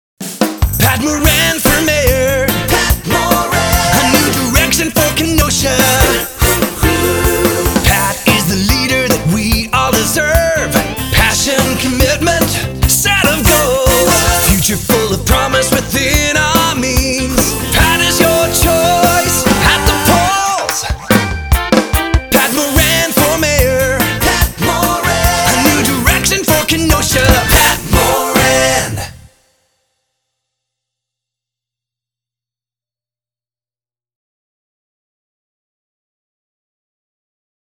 Tags: Jingle Music Marketing Musical Image Branding